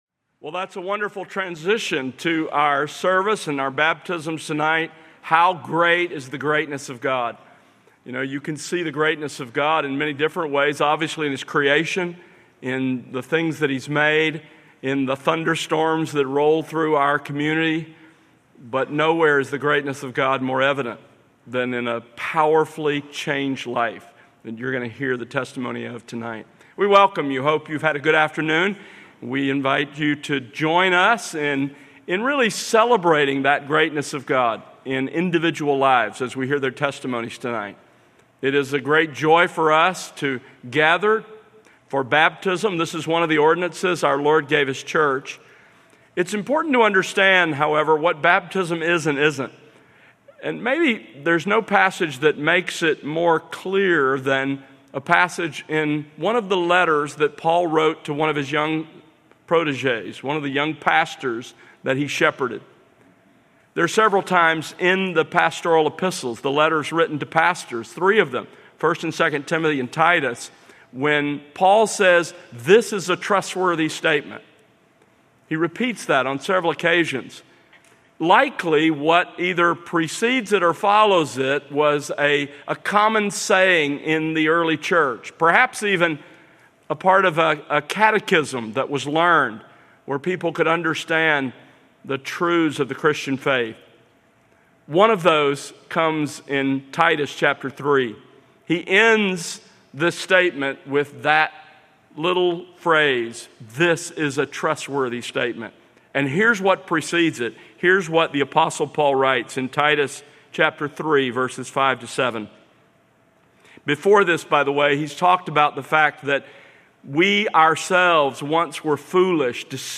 Baptisms